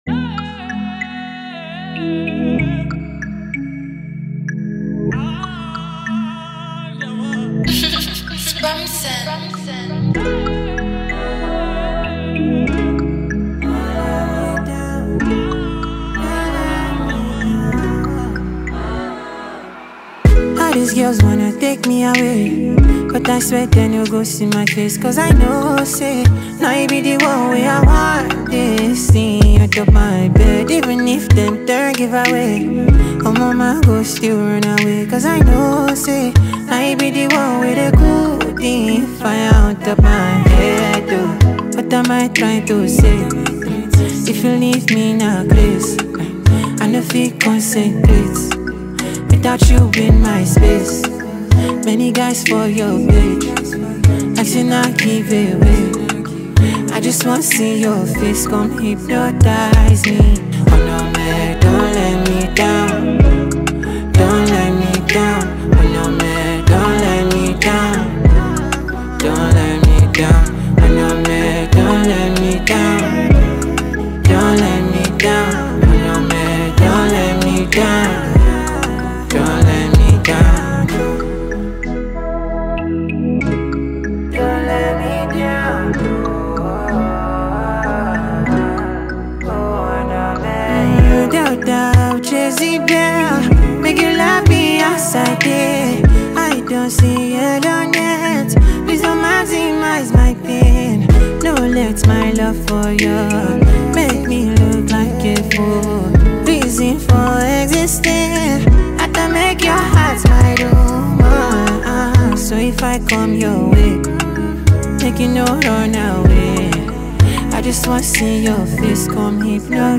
Well renowned Nigerian artist and performer
gbedu song